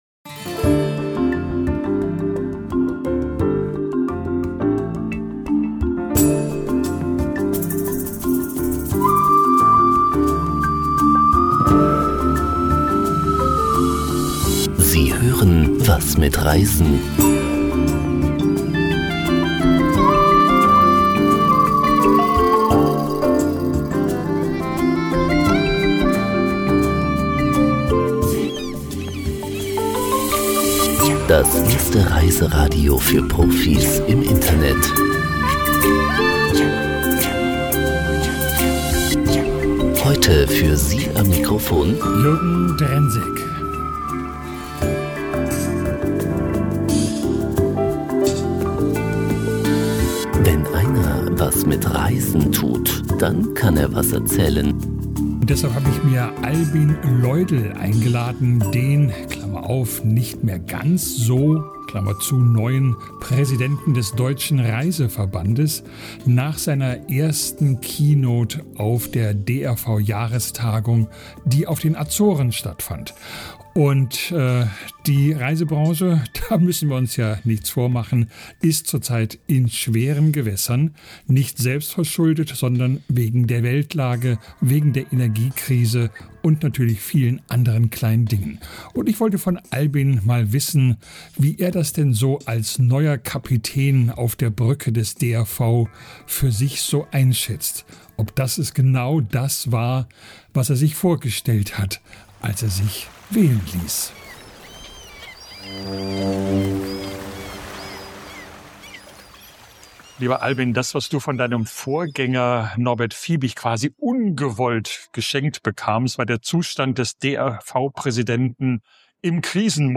Während die Branche zwischen Buchungsflaute und Preisschock balanciert, traf sich der DRV zur Jahrestagung auf den Azoren.